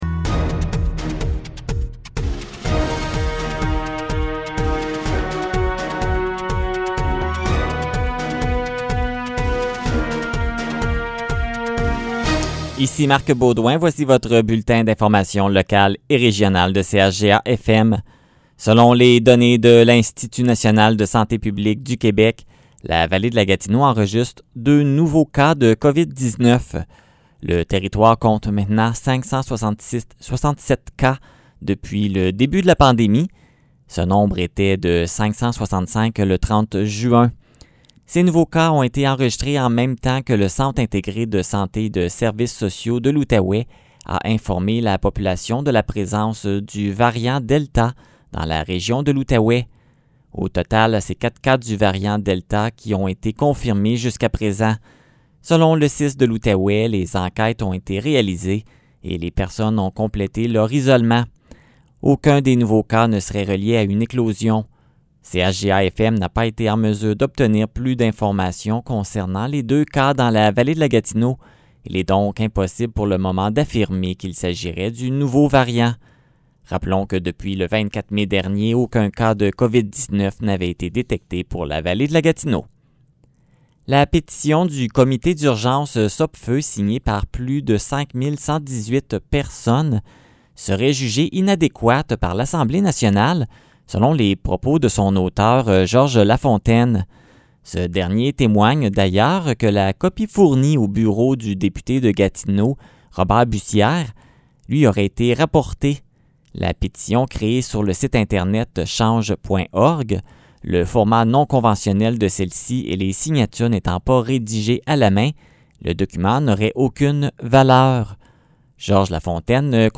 Nouvelles locales - 5 juillet 2021 - 15 h